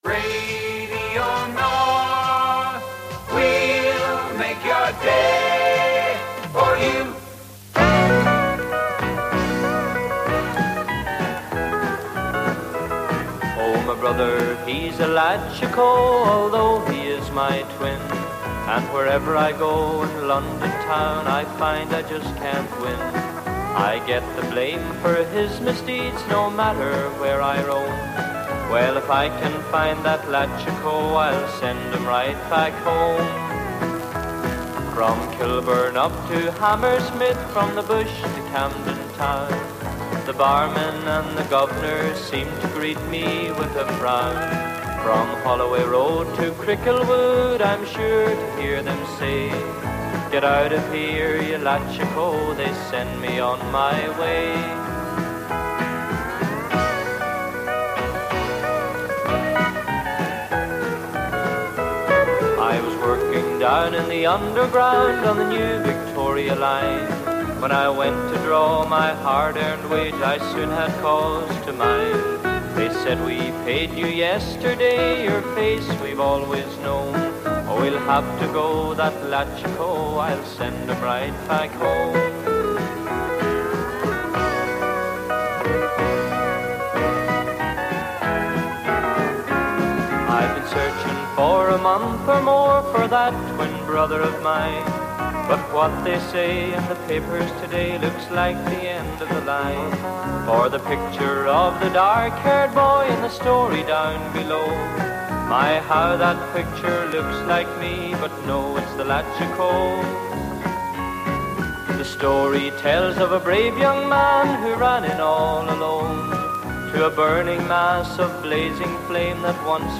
Along with the vast majority of the other pirates, the Donegal station Radio North closed down at the end of 1988 in compliance with new broadcasting legislation.